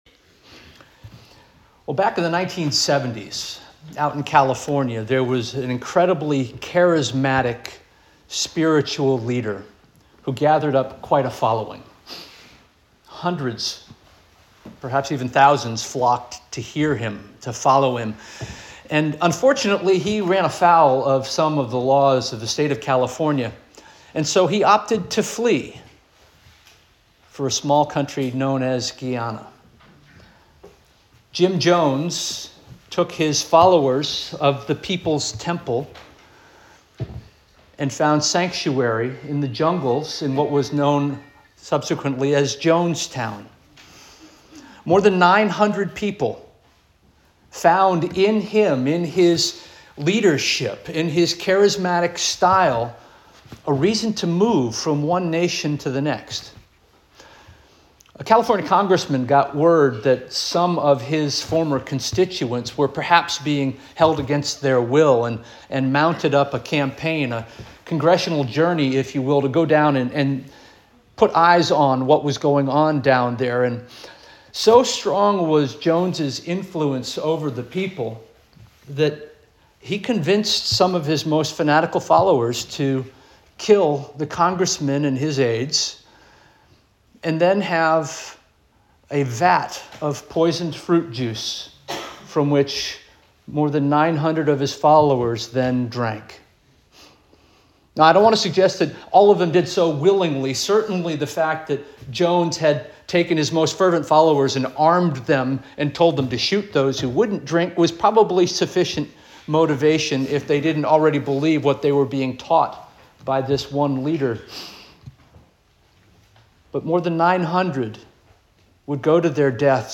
November 16 2025 Sermon - First Union African Baptist Church